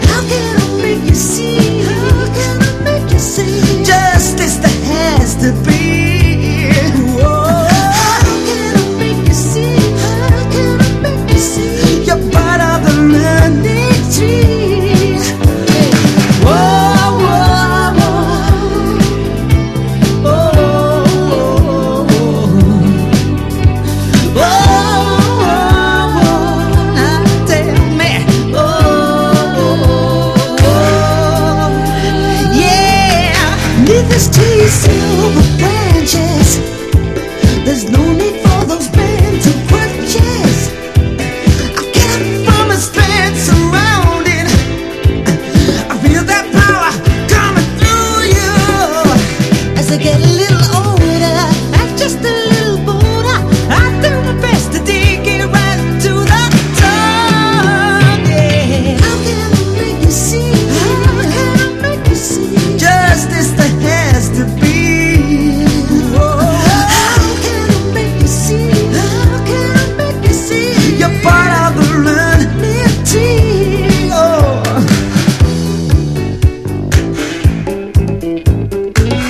ROCK / NEW WAVE / PUNK / GUITAR POP (UK)